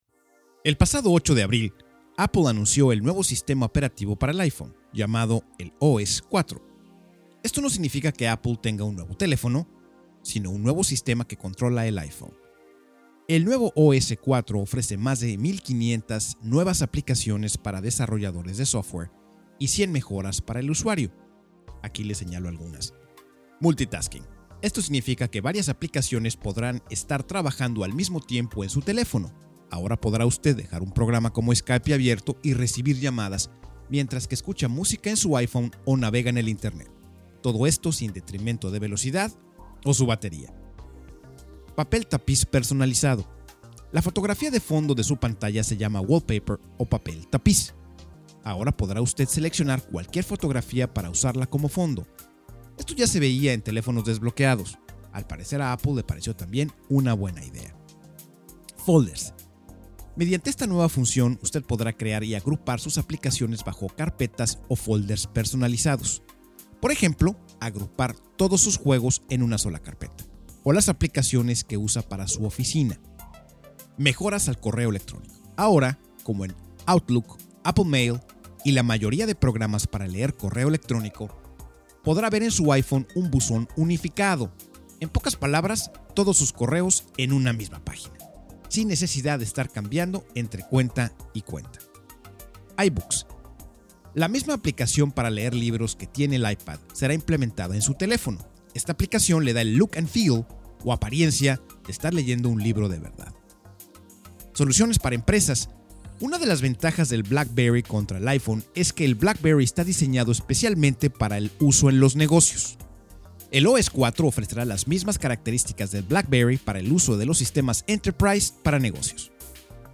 Capsulas para transmision en Radio.